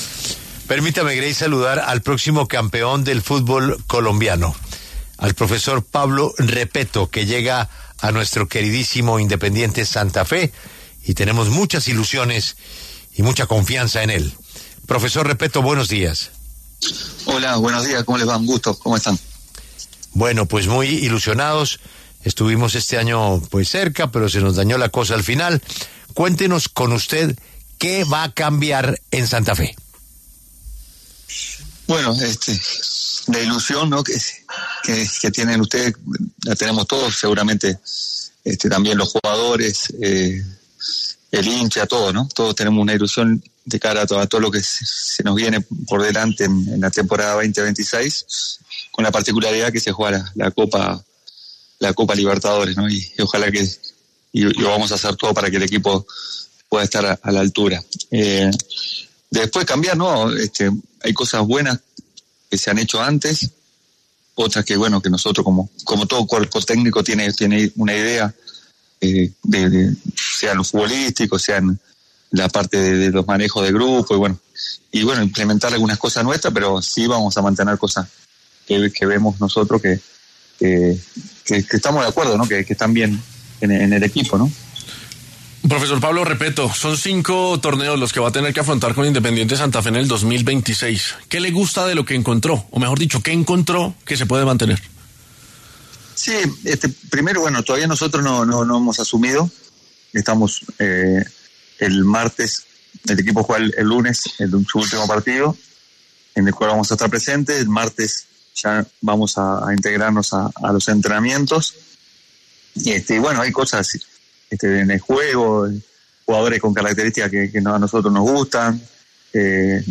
Por este motivo, el mismo Repetto habló en los micrófonos de La W, con Julio Sánchez Cristo, para conversar sobre lo que se viene para Santa Fe en el próximo 2026.